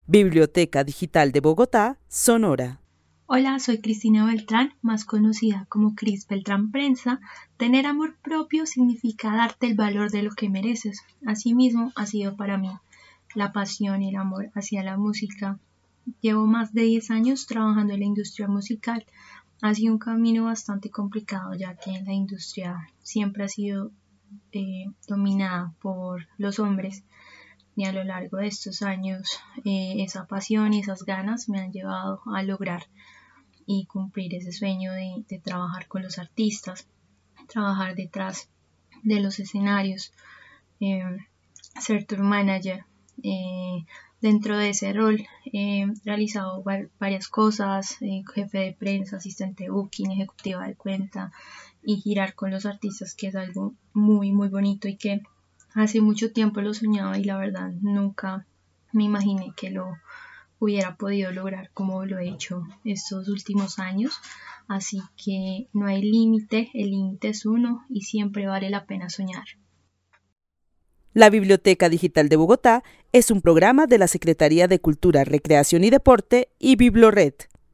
Narración oral de una mujer que vive en la ciudad de Bogotá y que desde su experiencia en la industria de la música define el amor propio como dar valor a lo que que se merece. Relata que la pasión y amor por lo que hace le ha permitido alcanzar varias de sus metas, en una industria dominada por hombres.